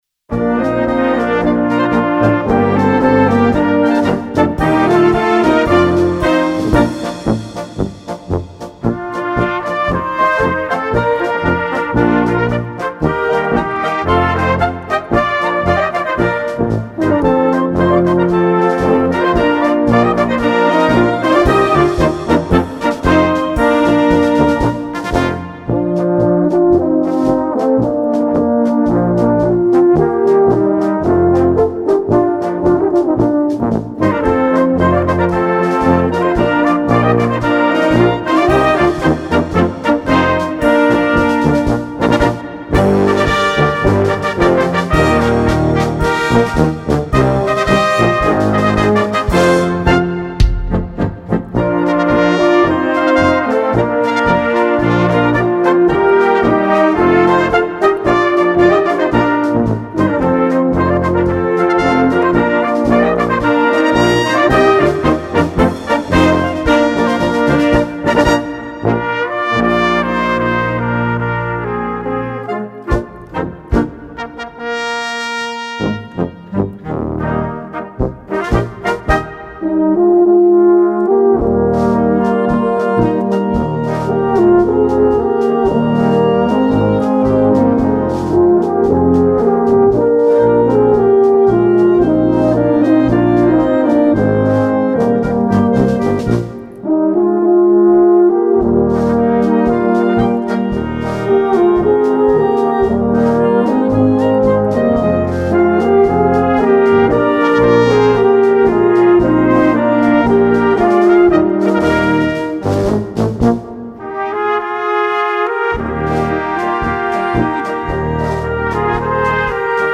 Gattung: Polka für Blasorchester
Besetzung: Blasorchester